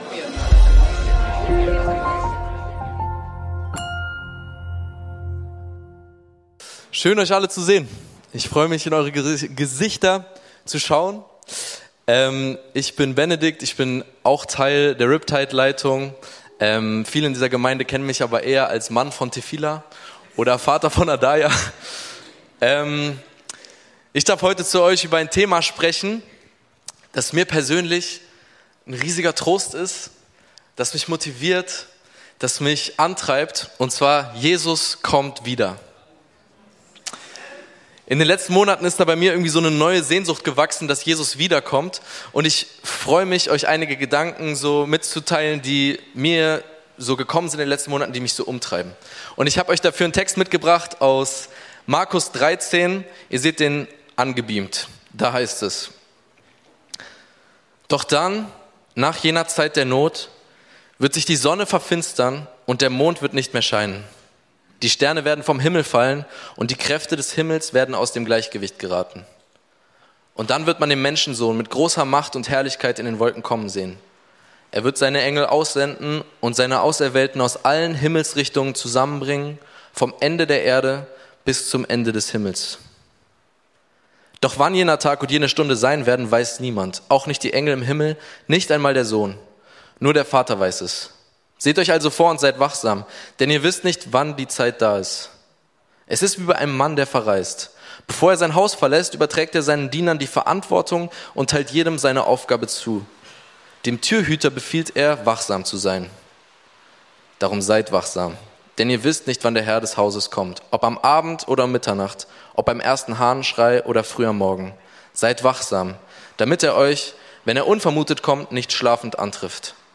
Riptidegottesdienst ~ Predigten der LUKAS GEMEINDE Podcast